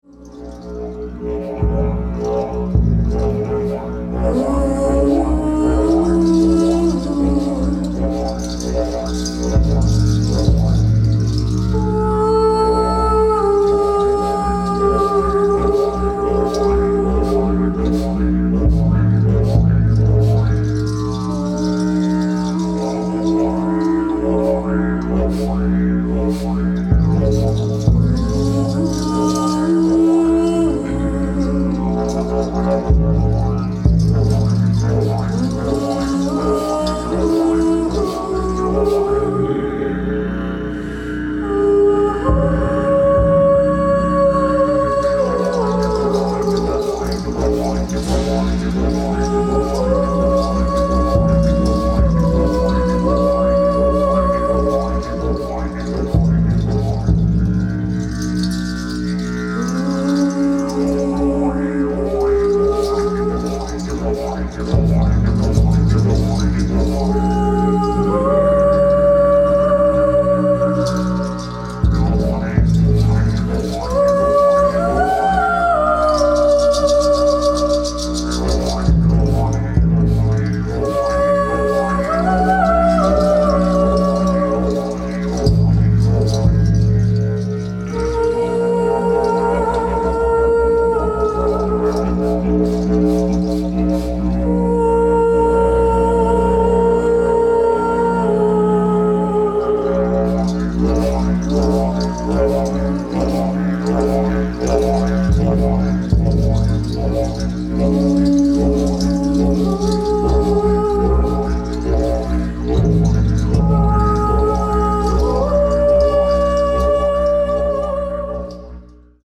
sound studio